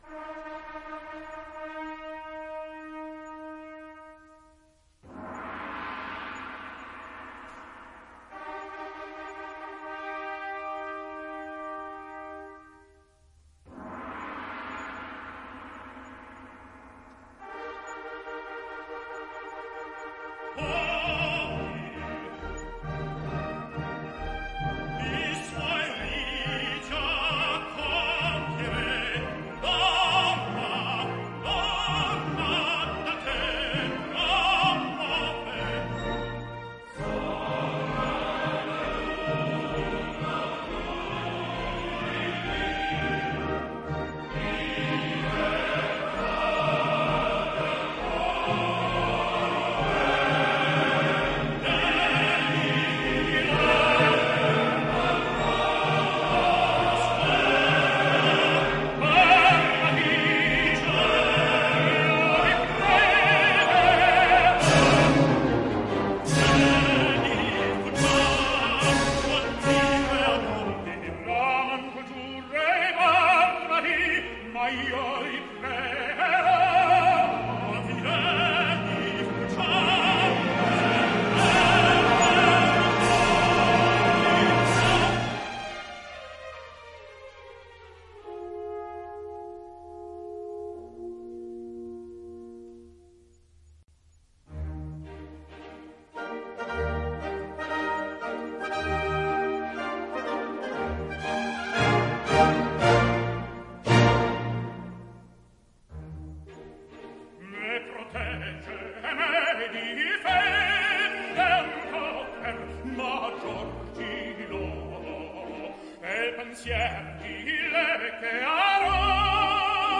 registrazione in studio.
Coro